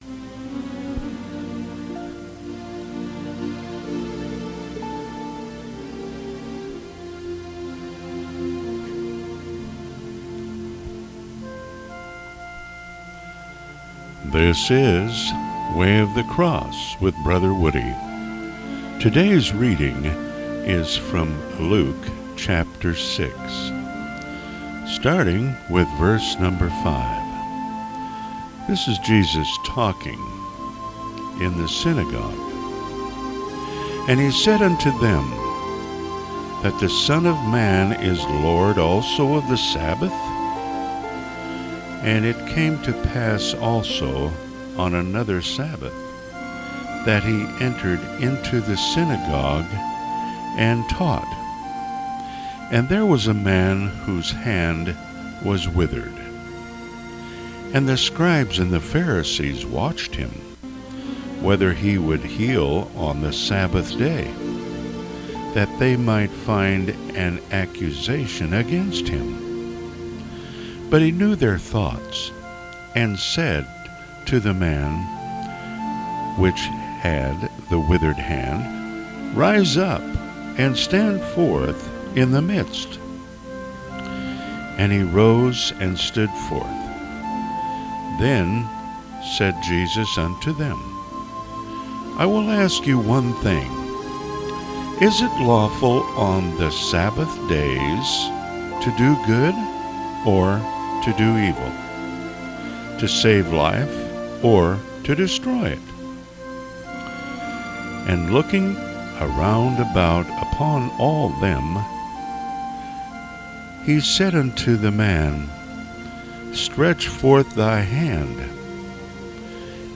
Bible readings